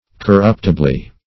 -- Cor*rupt"i*ble*ness, n. -- Cor*rupt"i*bly, adv.